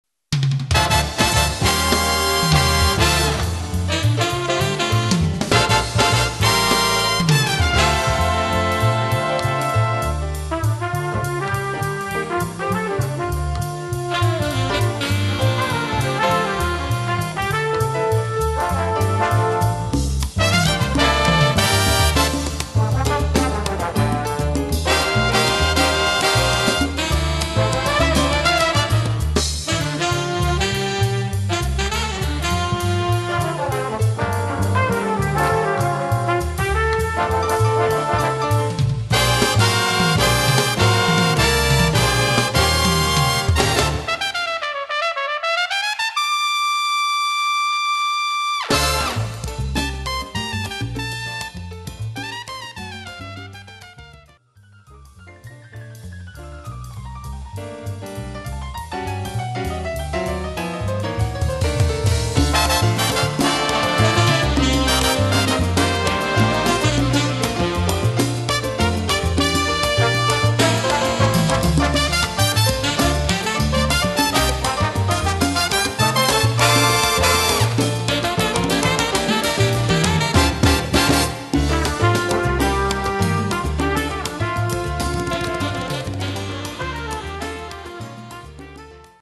Category: big band
Style: mambo
Instrumentation: big band (4-4-5, rhythm (4)